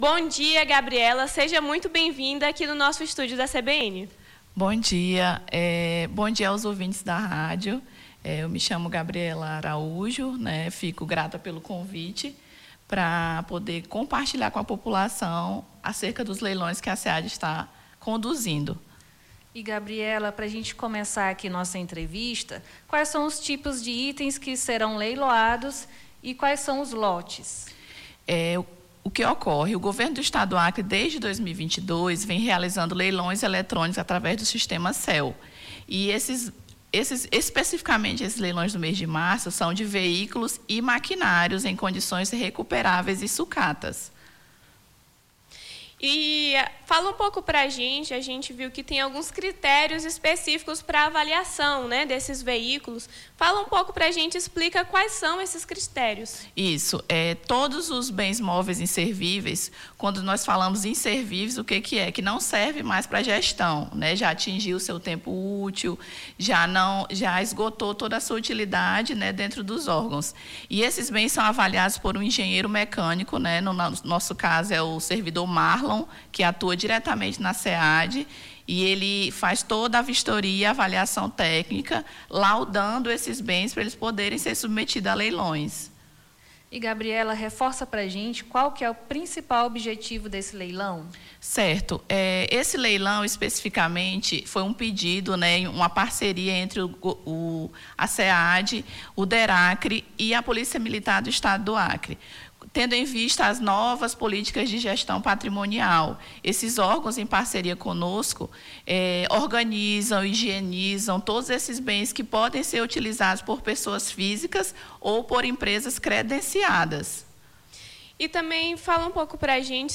Nome do Artista - CENSURA - ENTREVISTA LEILAO VEICULOS GOV - 24-02-26.mp3